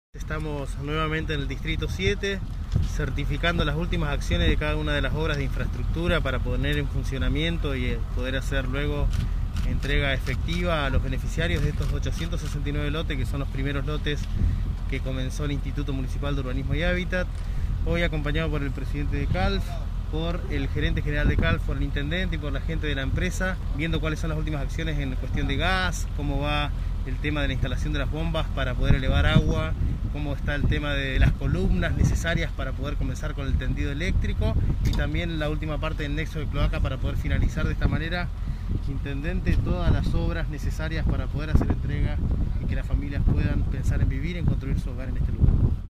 Marco Zapata, titular IMUH.